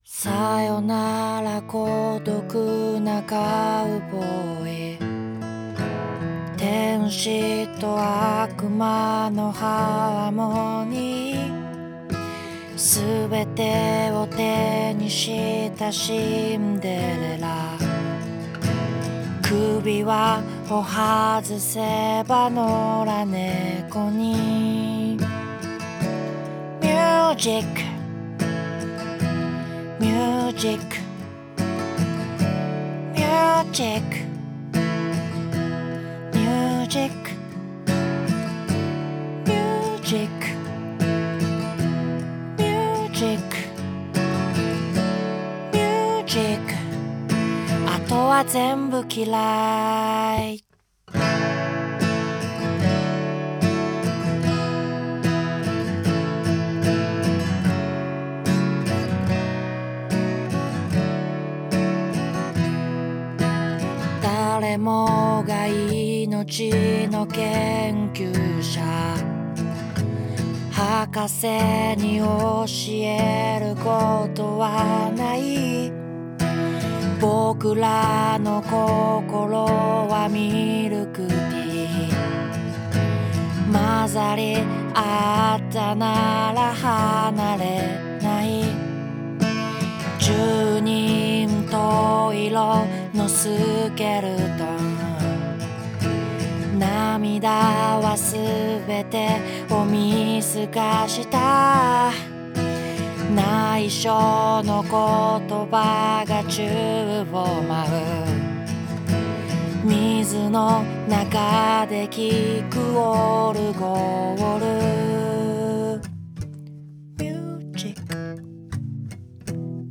今回は、ボーカルには1073LB、1073LBEQ、2264ALBの順番で信号を通して録音しています。
Neveらしさがよく分かる音源になったと思います。